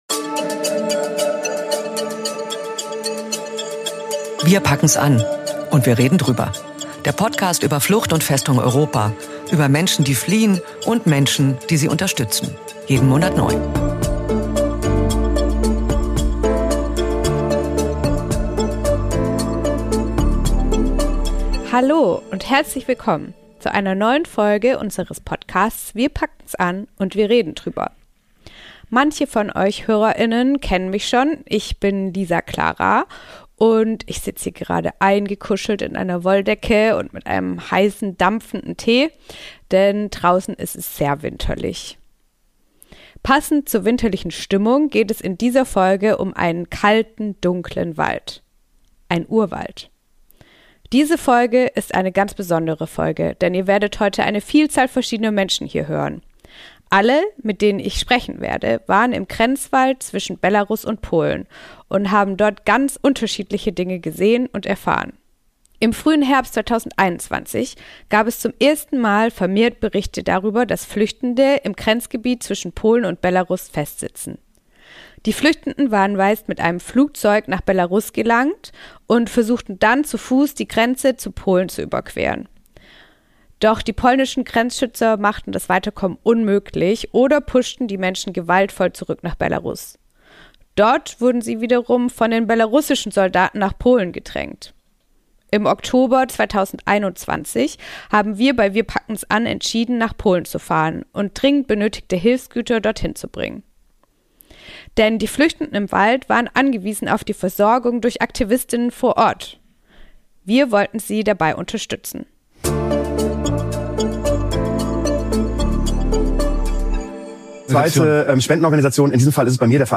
Verschiedene Stimmen erzählen von der Arbeit an der polnisch-belarussischen Grenze und der dramatischen Situation für Geflüchtete, die diese Grenze versuchen zu überqueren.